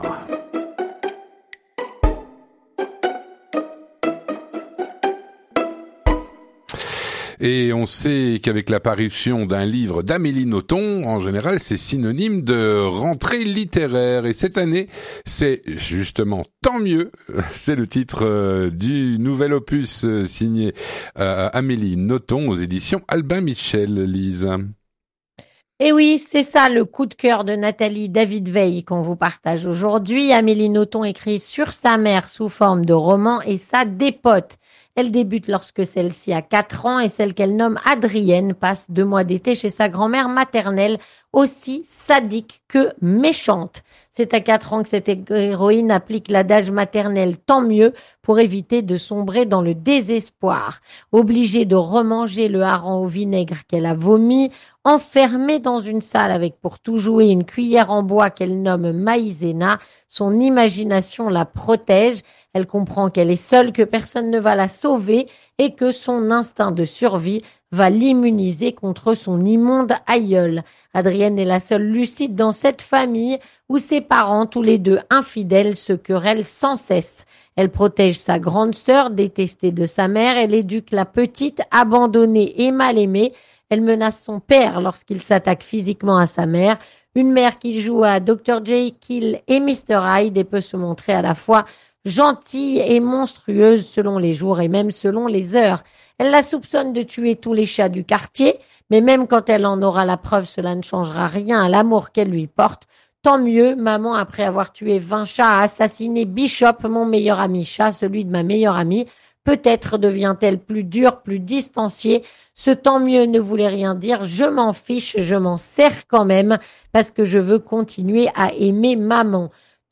une chronique